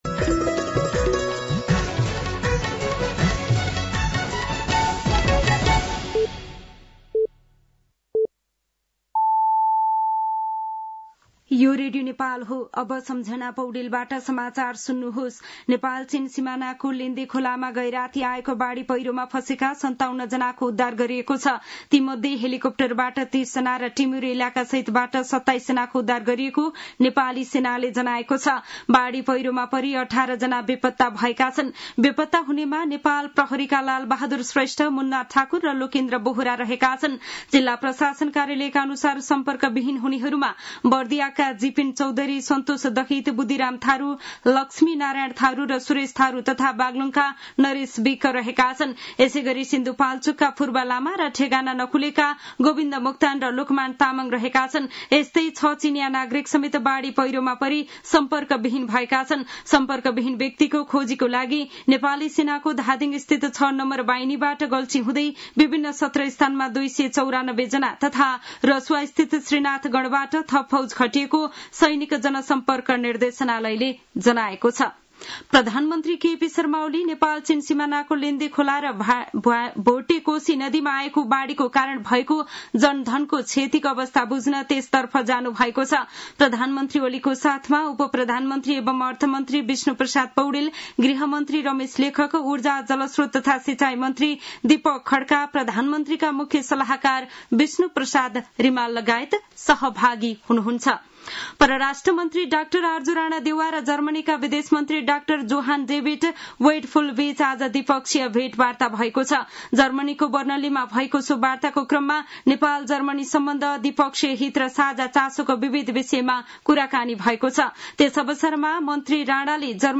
An online outlet of Nepal's national radio broadcaster
साँझ ५ बजेको नेपाली समाचार : २४ असार , २०८२